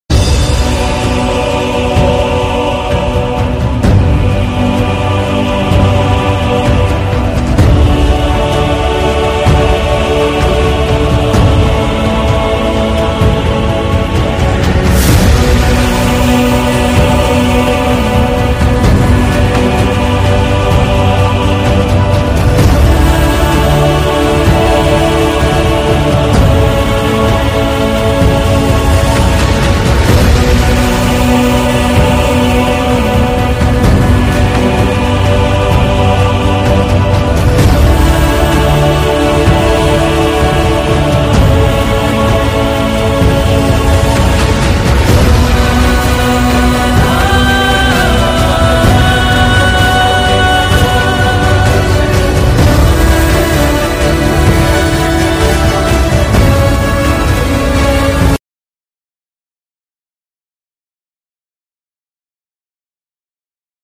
This Heavy Lift Sky Crane Sound Effects Free Download